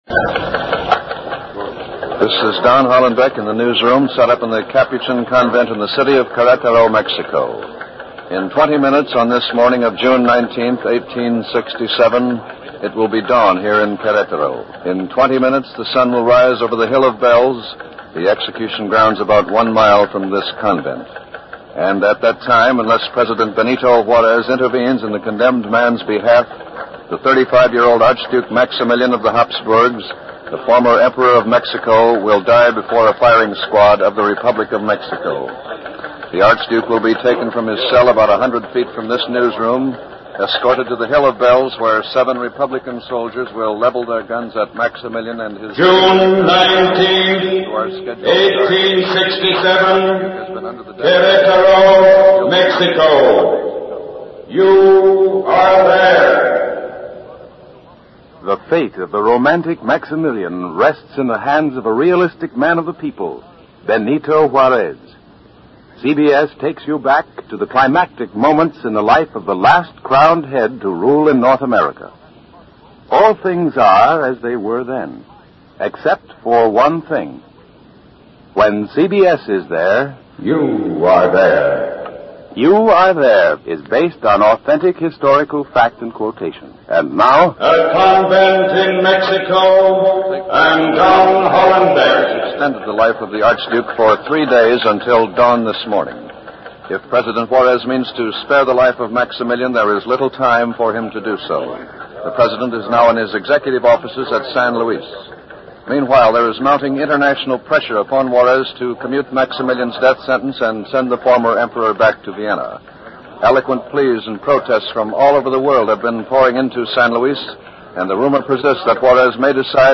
Lewis and Clark: old time radio